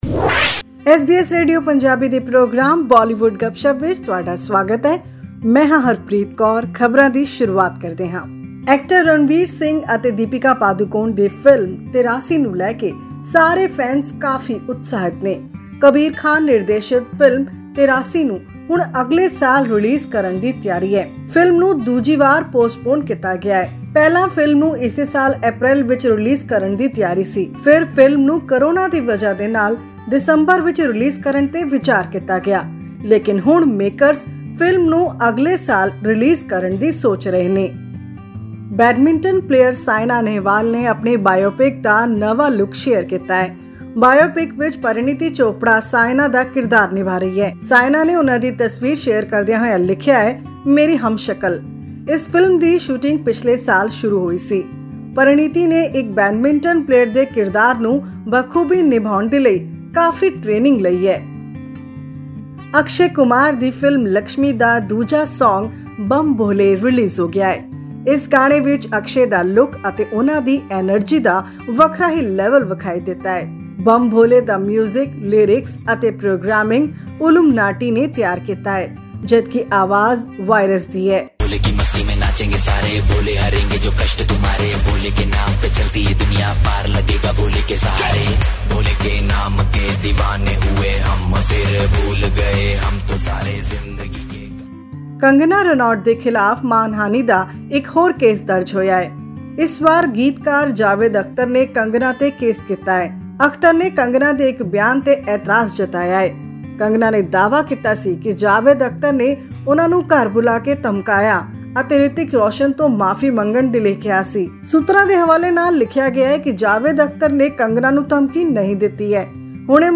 Listen to this and other stories in our weekly report from Bollywood.